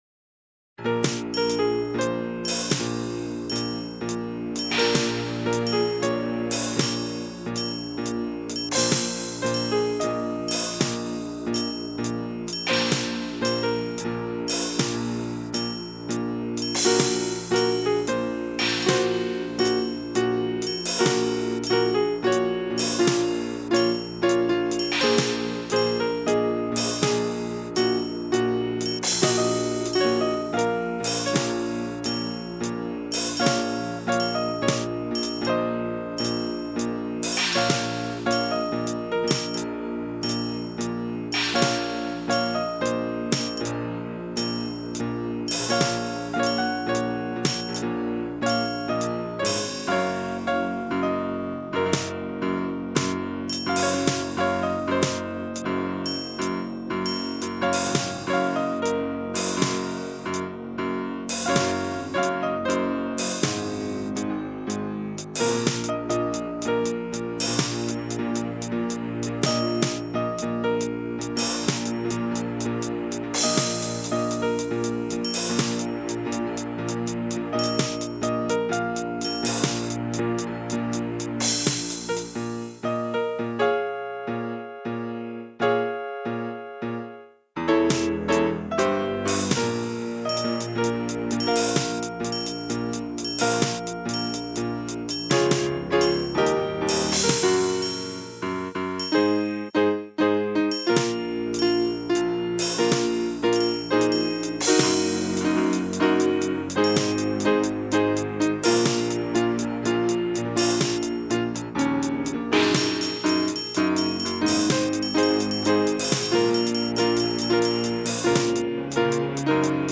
I tried some percussion on this piece but I'm Not strong on the timing.